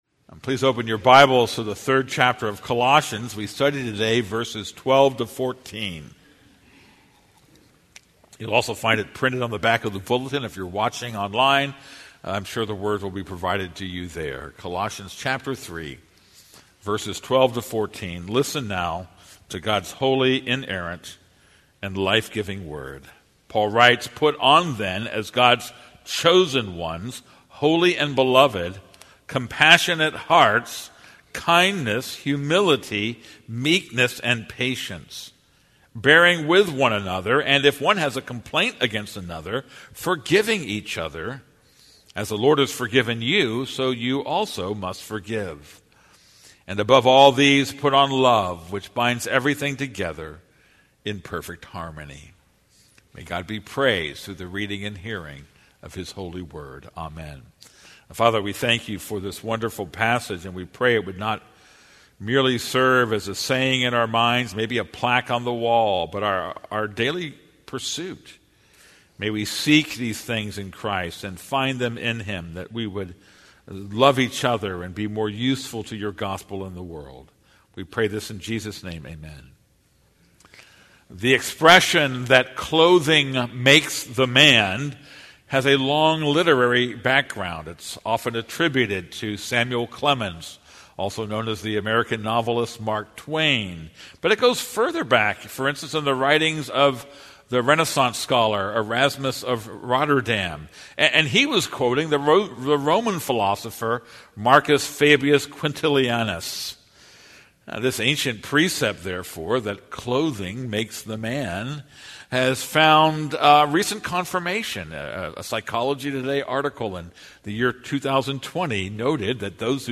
This is a sermon on Colossians 3:12-14.